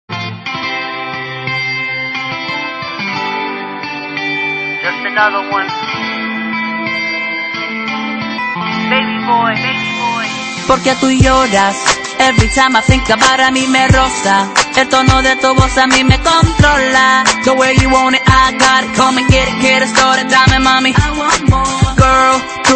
• Latin Ringtones